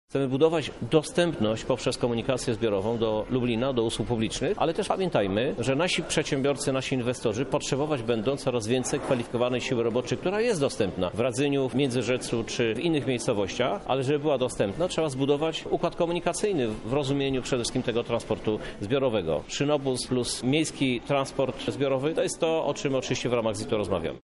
W jej ramach ma powstać spójna infrastruktura transportowa, o której mówi Krzysztof Żuk, prezydent Lublina.